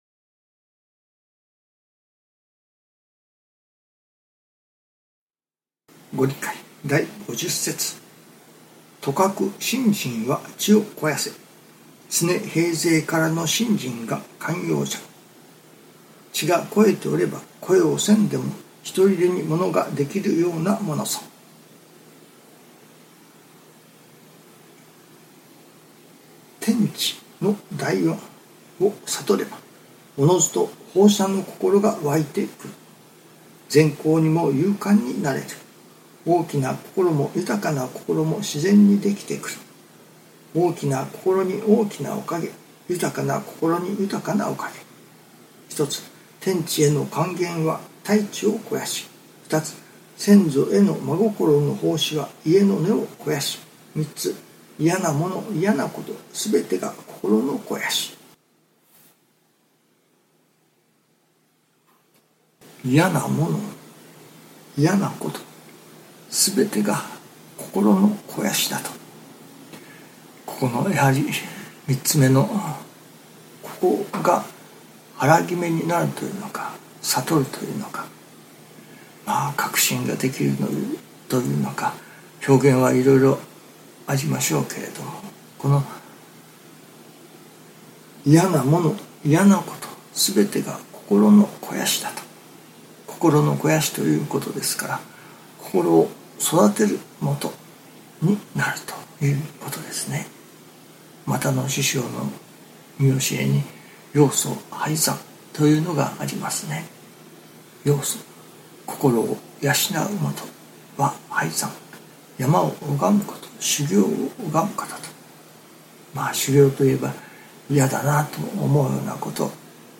今朝は音声の収録に失敗した。マイクの録音ボタンを押すのを忘れていた。だからカメラの音を使う事になる。